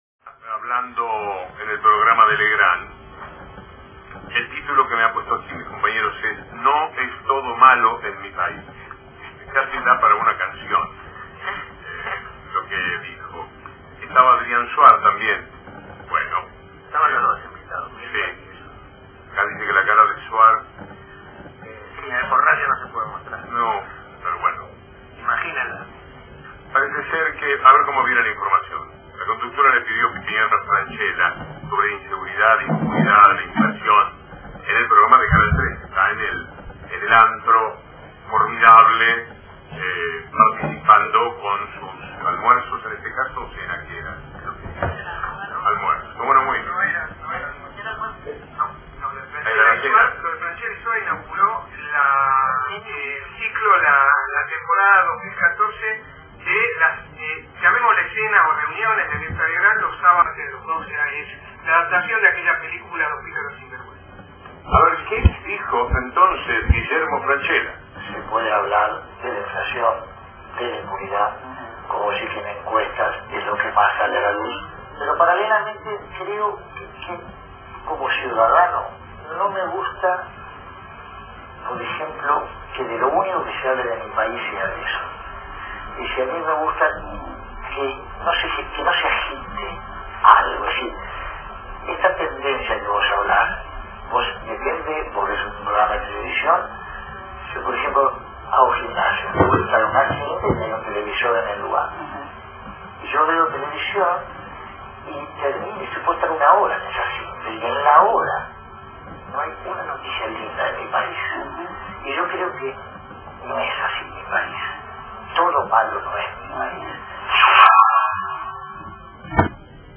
Agradecemos infinitamente a Victor Hugo por recomendarnos y por leer al aire varias de las noticias que publicamos diariamente en su programa La Mañana por Radio Continental AM590. Aqui un extracto del programa emitido el 31 de Marzo del 2014 : Audio